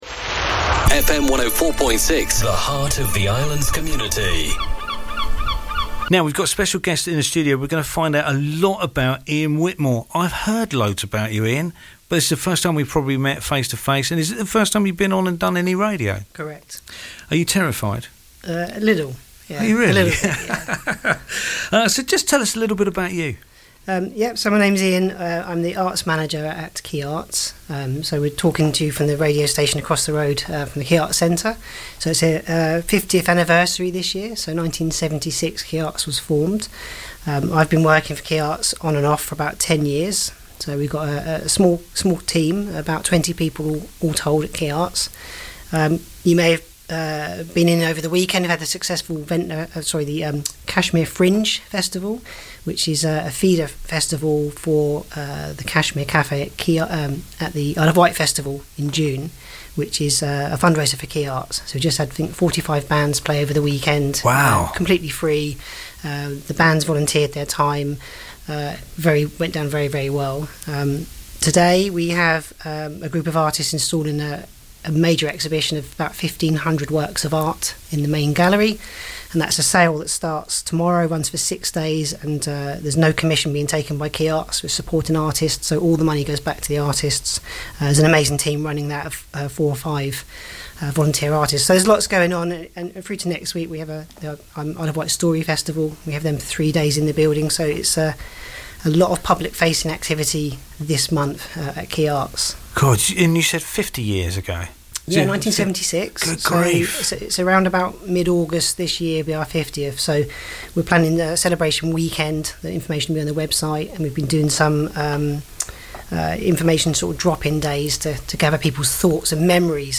Vectis Interviews 2026